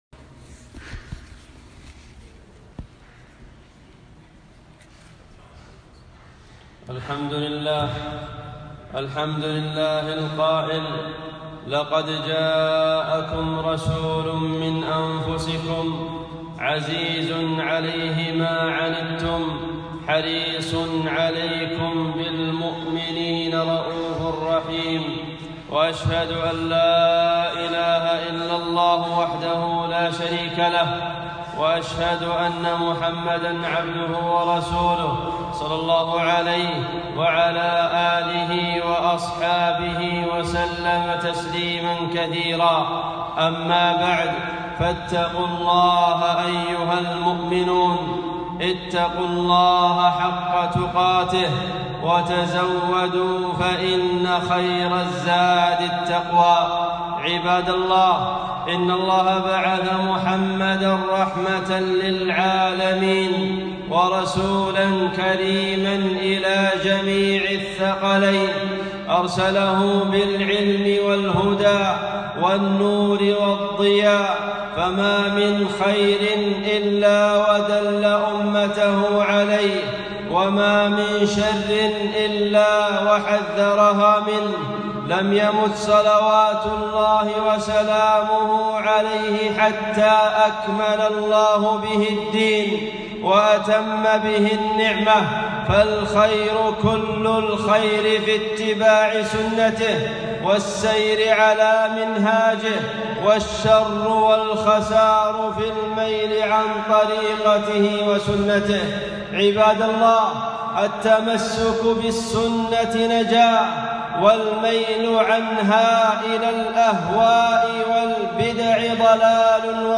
خطبة - بدعة المولد